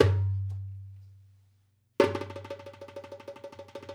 Dumbek 01.wav